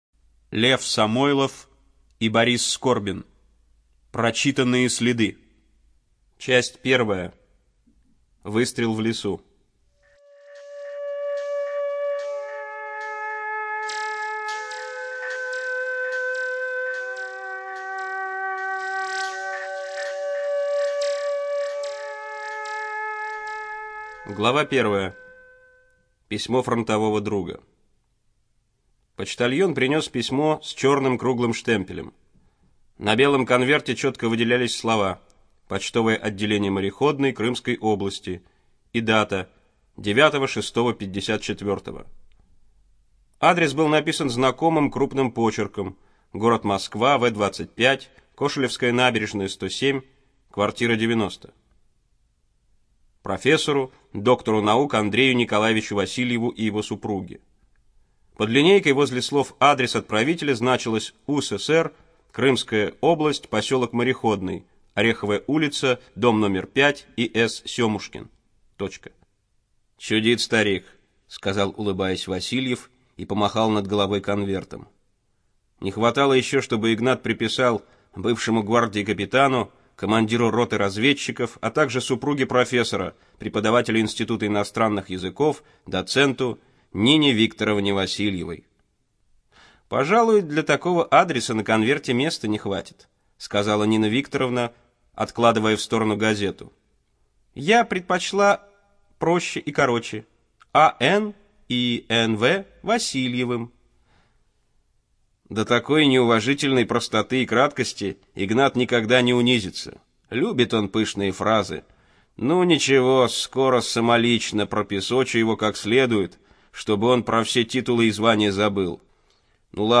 Эту и другие книги нашей библиотеки можно прослушать без использования компьютера с помощью Android-приложения или тифлофлешплеера с поддержкой онлайн-доступа.